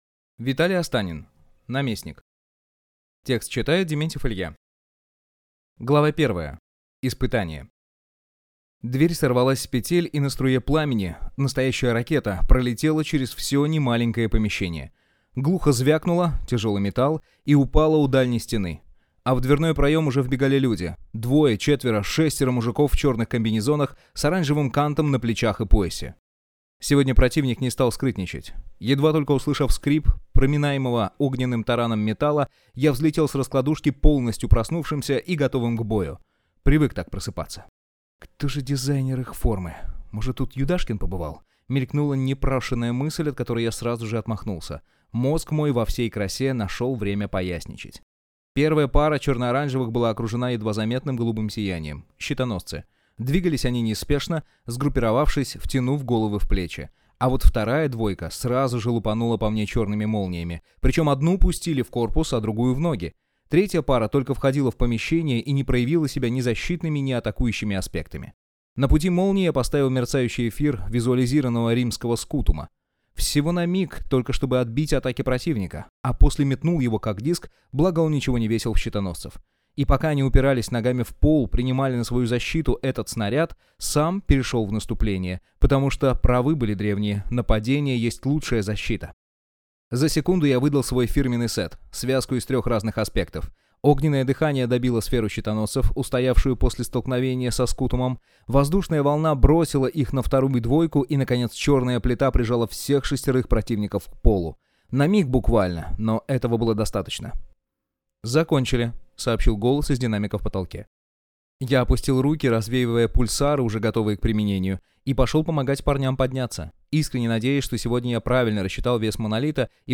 Аудиокнига Наместник | Библиотека аудиокниг